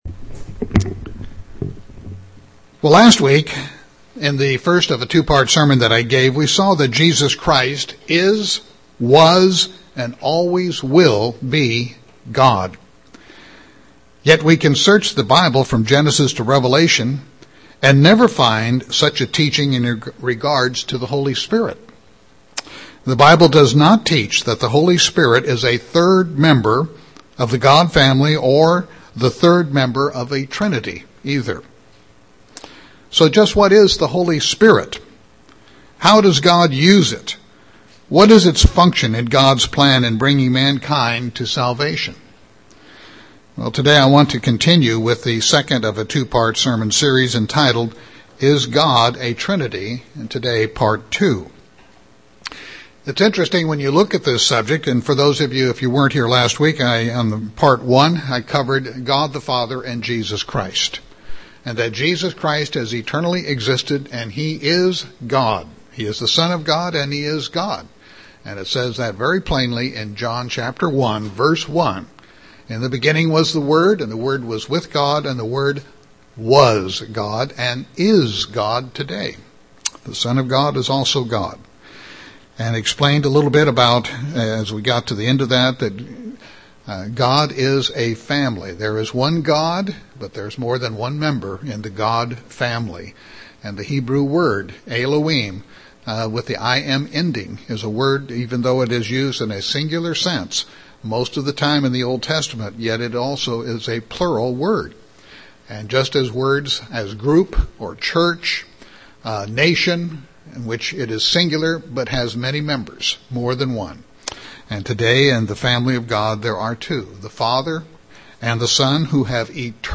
5-05-12 Sermon.mp3